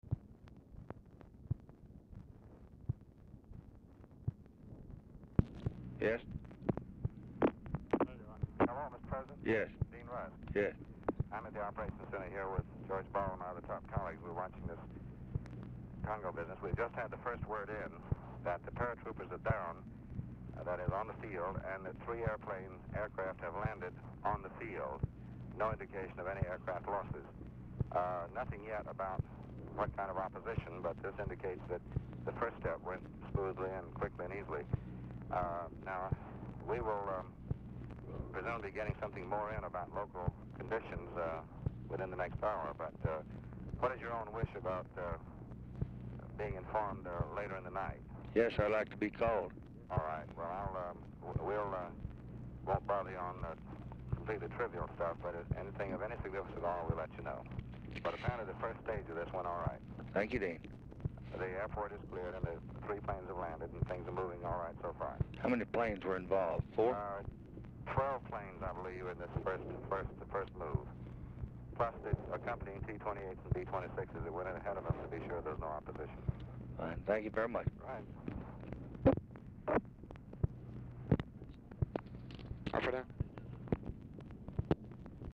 Telephone conversation # 6465, sound recording, LBJ and DEAN RUSK, 11/23/1964, 11:18PM | Discover LBJ
Format Dictation belt
Location Of Speaker 1 LBJ Ranch, near Stonewall, Texas
Specific Item Type Telephone conversation